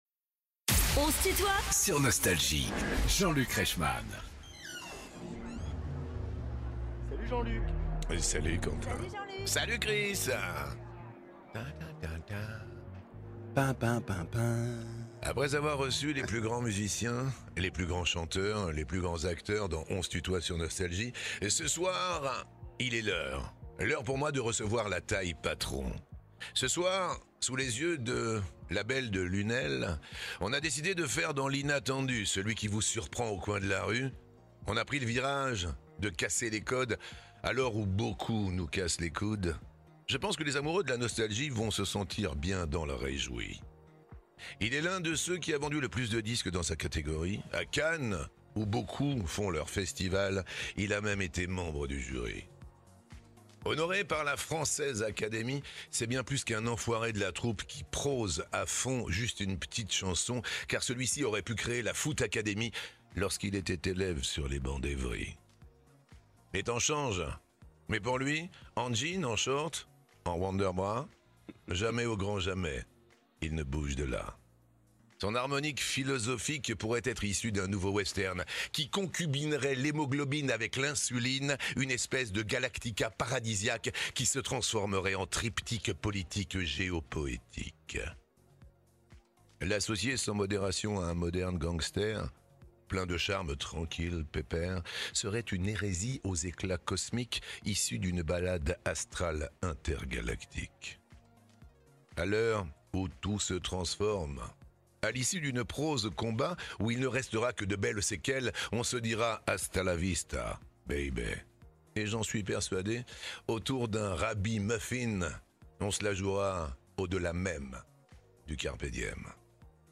MC Solaar est l'invité de "On se tutoie ?..." avec Jean-Luc Reichmann (partie 1) ~ Les interviews Podcast